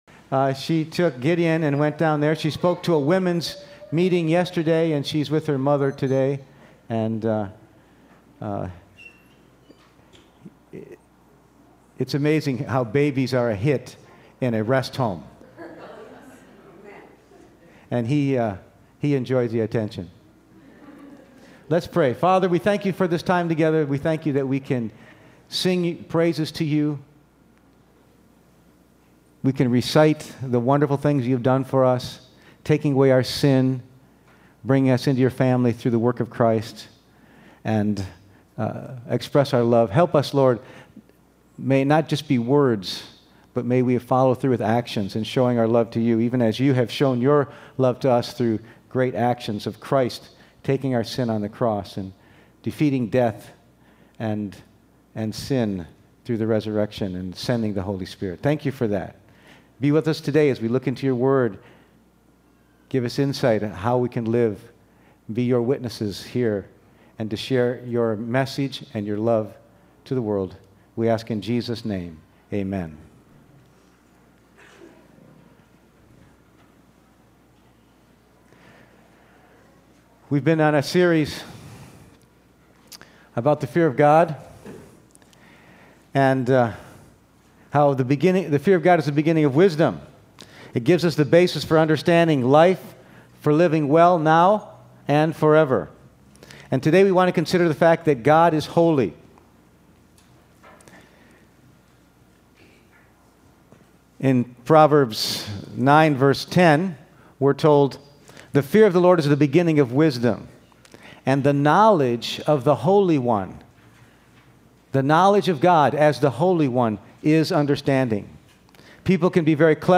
Passage: Proverbs 9:10, 1 Peter 1:13-19 Service Type: Sunday Morning